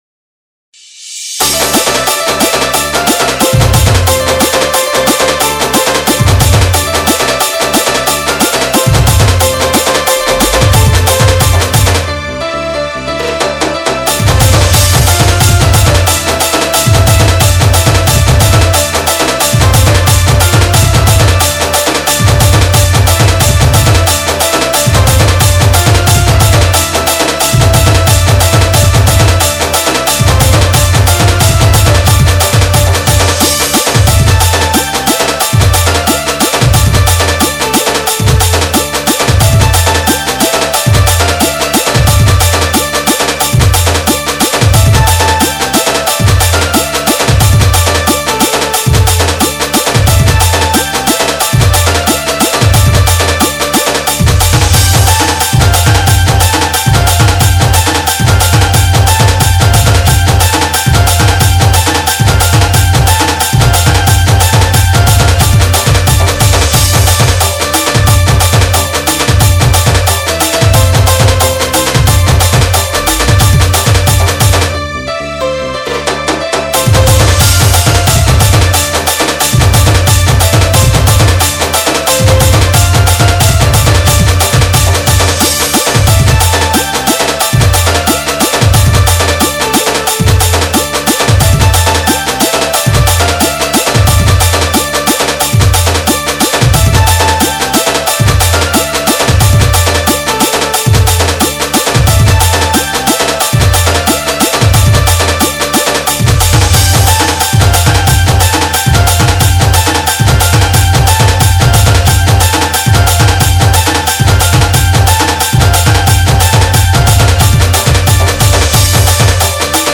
SAMBALPURI INSTRUMENT DJ REMIX